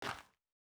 Shoe Step Gravel Medium E.wav